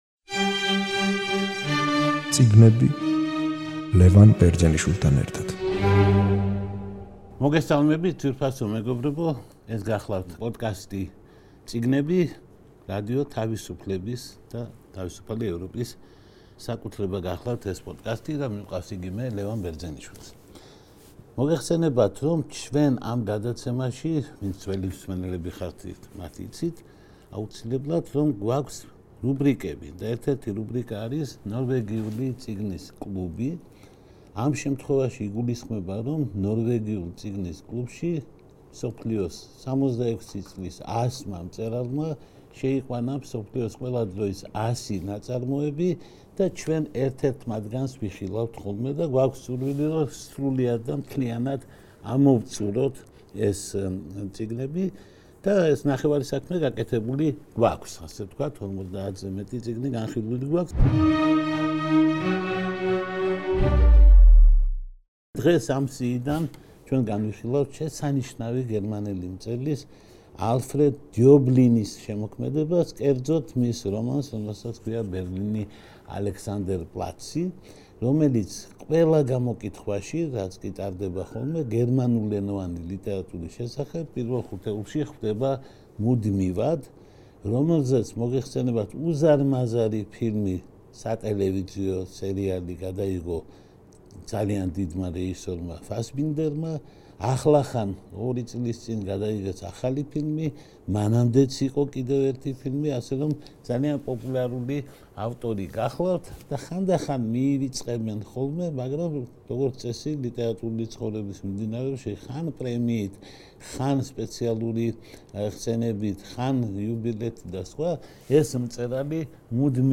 რადიო თავისუფლების პოდკასტი „წიგნები“ რუბრიკით „ნორვეგიული წიგნის კლუბი“ გთავაზობთ საუბარს გერმანული ექსპრესიონიზმისა და ევროპული მაგიური რეალიზმის შედევრზე, რომელიც მუდმივად ხვდება გერმანულენოვანი რომანების ტოპ ხუთეულში, ალფრედ დიობლინის ნაშრომზე „ბერლინი, ალექსანდერპლაცი“. გერმანული...